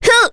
Maria-Vox_Attack1.wav